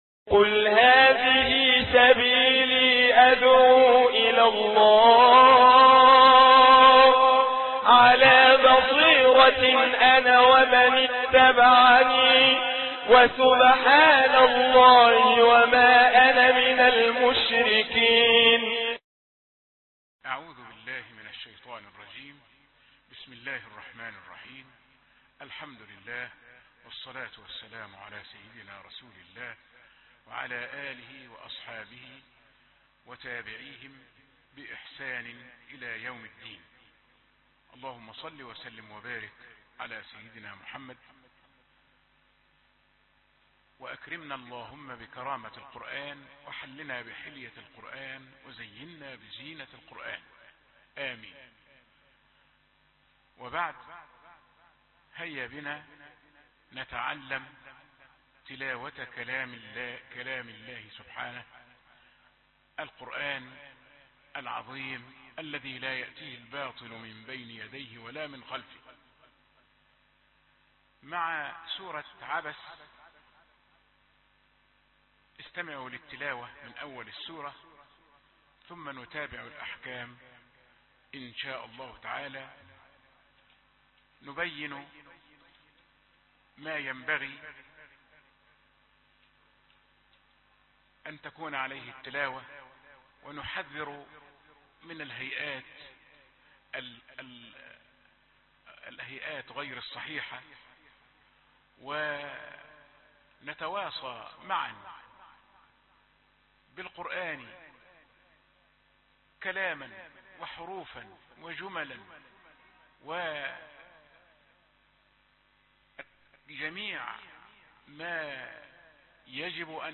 سورة عبس - تيسير تلاوة القران برواية حفص